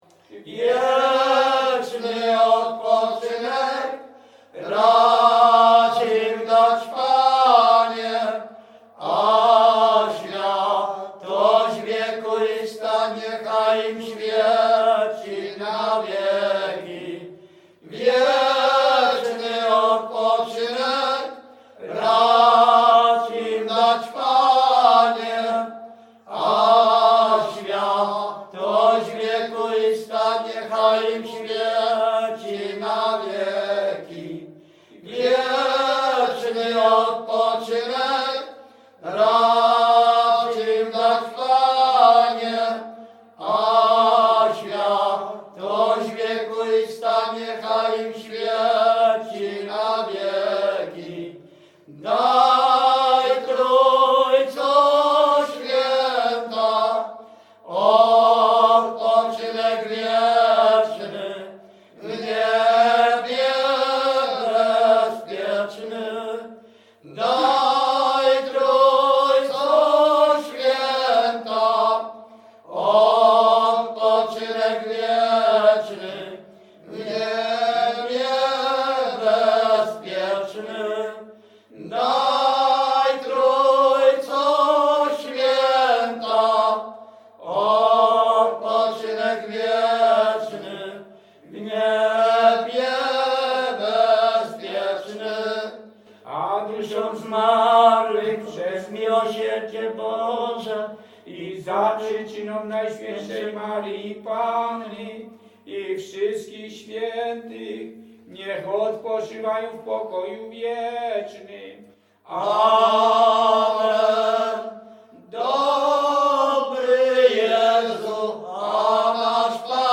Śpiewacy z Ruszkowa Pierwszego
Wielkopolska, powiat kolski, gmina Kościelec, wieś Ruszków Pierwszy
Egzorta
pogrzebowe
Wieczny odpoczynek_Egzorta_Dobry Jezu a nasz Panie.wav.mp3